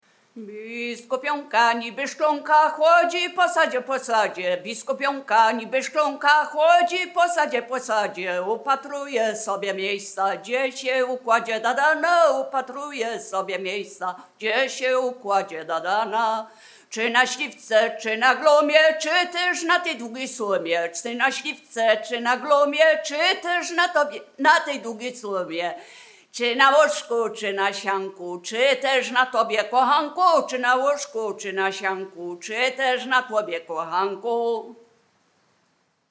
Wielkopolska
Obyczajowa